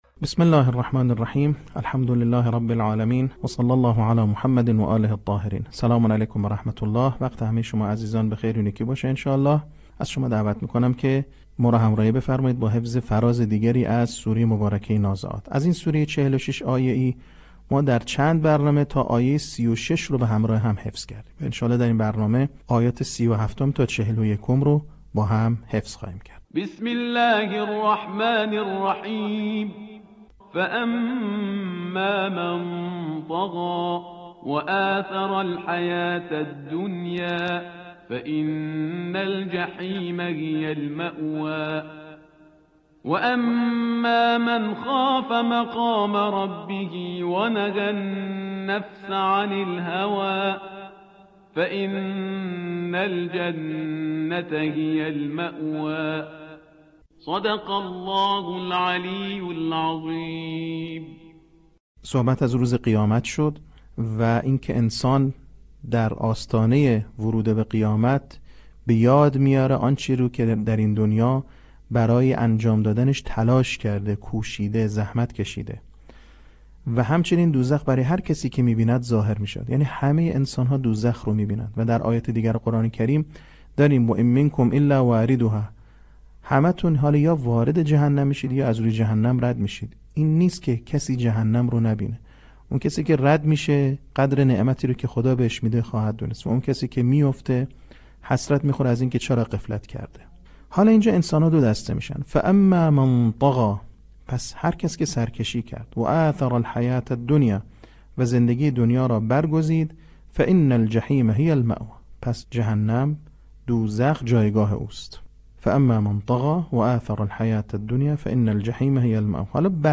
صوت | بخش ششم آموزش حفظ سوره نازعات
به همین منظور مجموعه آموزشی شنیداری (صوتی) قرآنی را گردآوری و برای علاقه‌مندان بازنشر می‌کند.